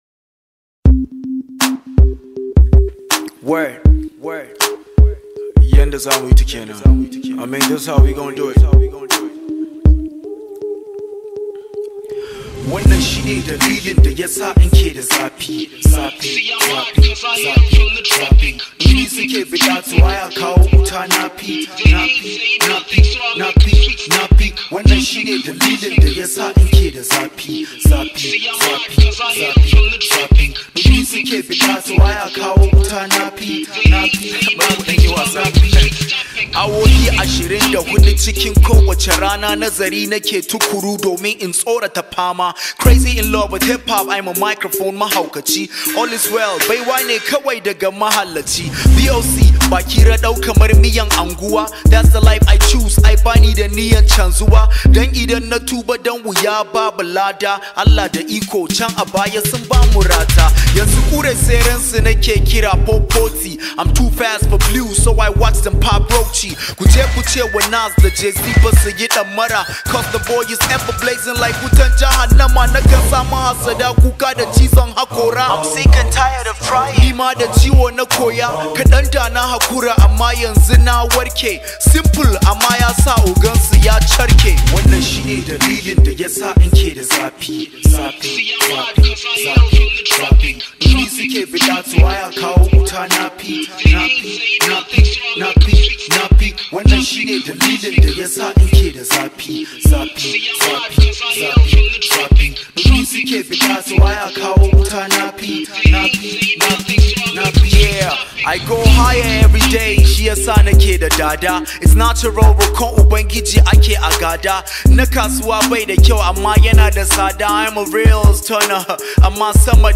top rated Nigerian Hausa Music artist
This high vibe hausa song